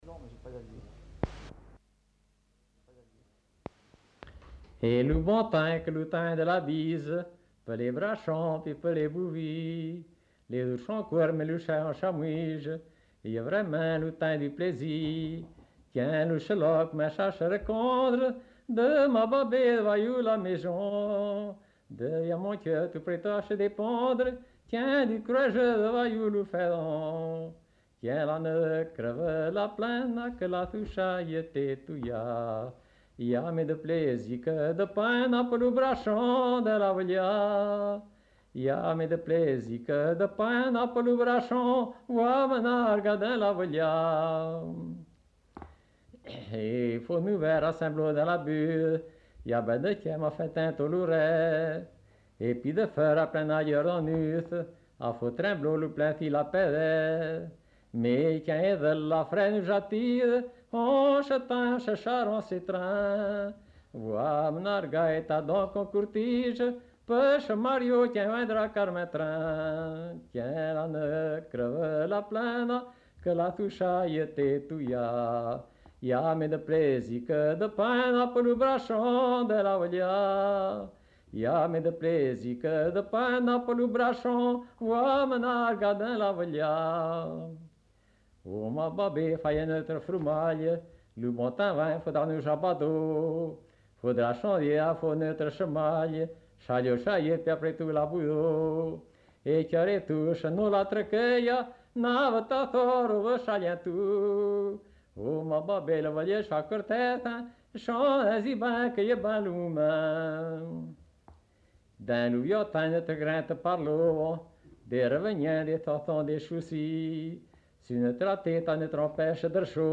chant